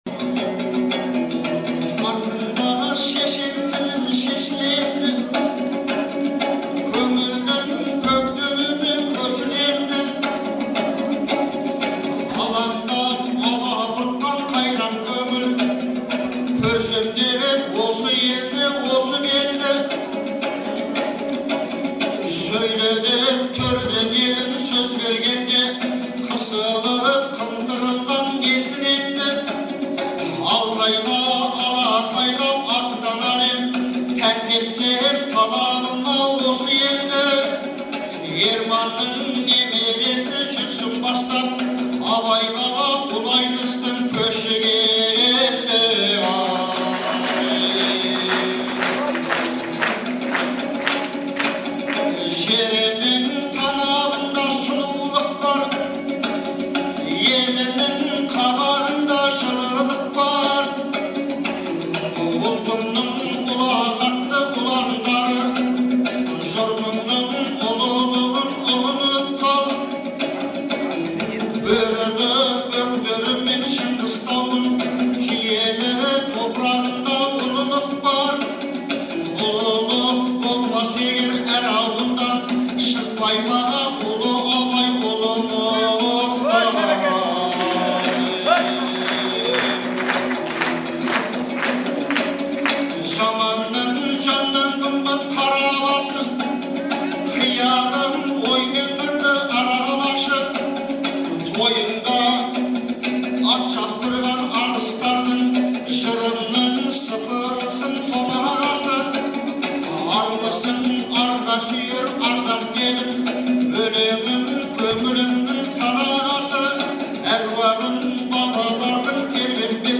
Қарауылдағы айтыс
Қыркүйектің 15-і күні Шығыс Қазақстан облысы Абай ауданының орталығы Қарауылда «Бабалар тойы – ел тойы» деген атпен Көкбай Жанатайұлы мен Ақылбайдың туғанына 150 жыл, Шәкір Әбеновтің туғанына 110 жыл толуына орай республикалық айтыс өтті.